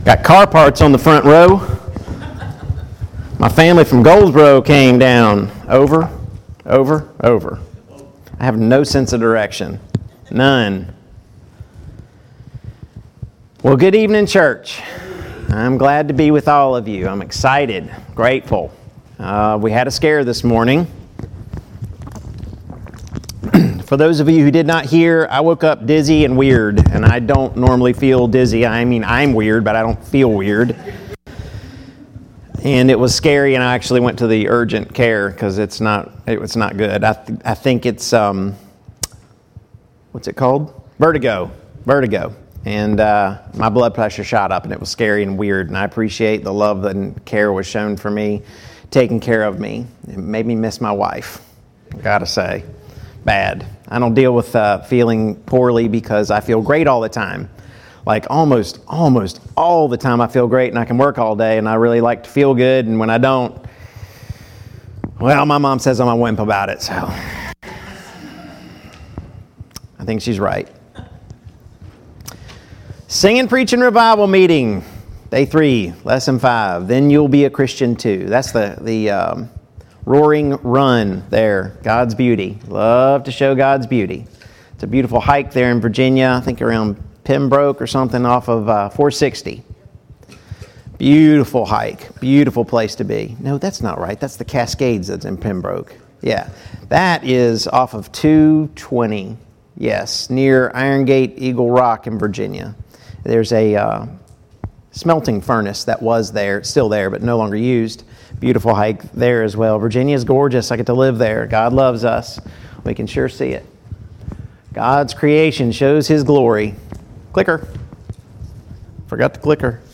2022 Spring Gospel Meeting Service Type: Gospel Meeting Download Files Notes Topics: The Plan of Salvation « 4.